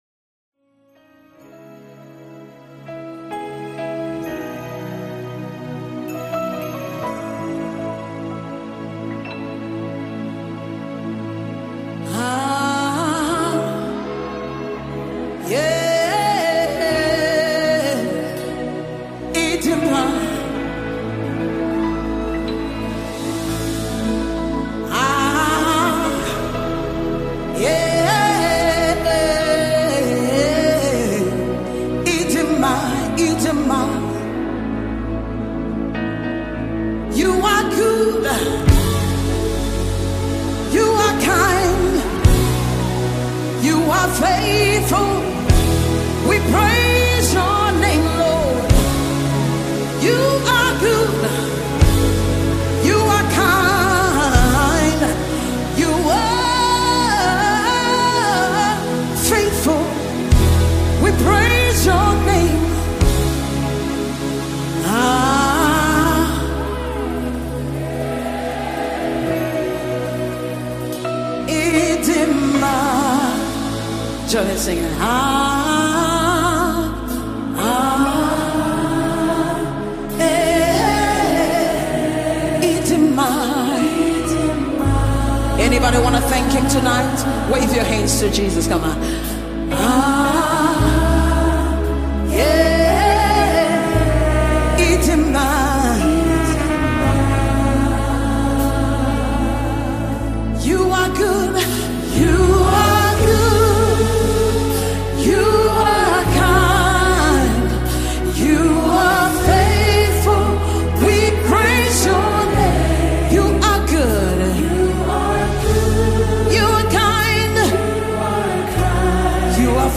This powerful worship anthem